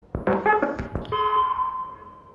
Arc Raiders Probe sound before a breach attempt has been made